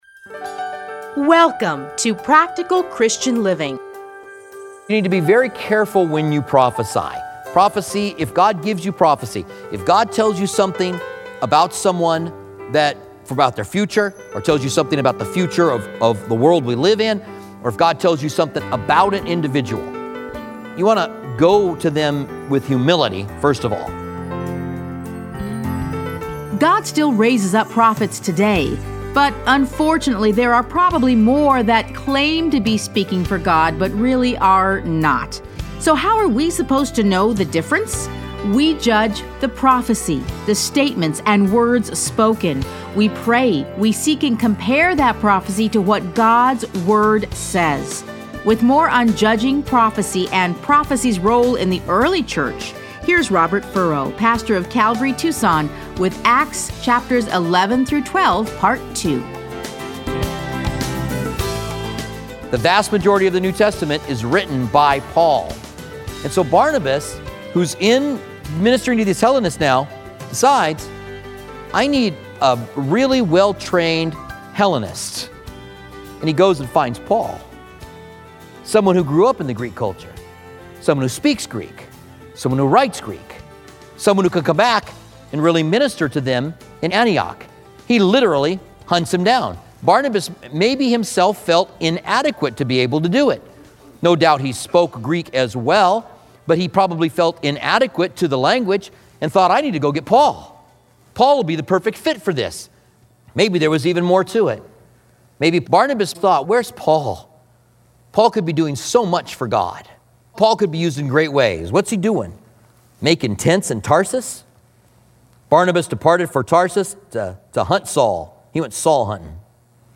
Listen to a teaching from Acts 11 - 12.